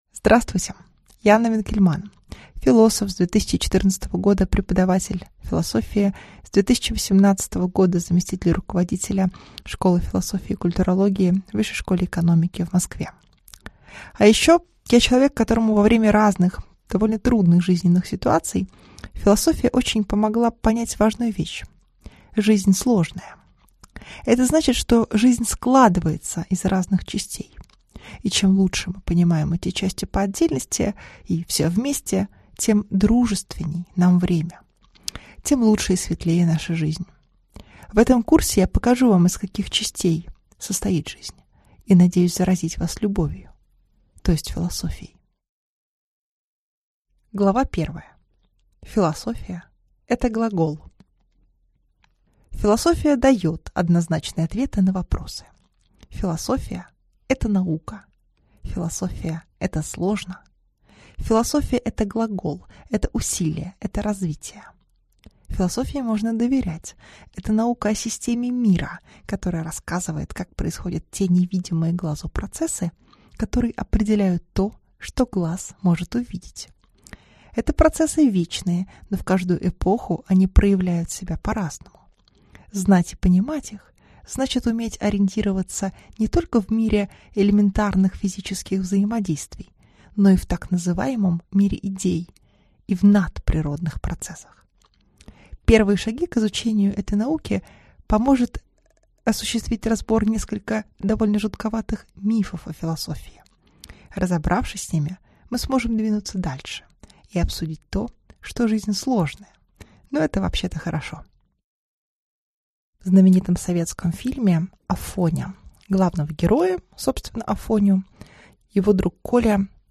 Аудиокнига Философия – это глагол | Библиотека аудиокниг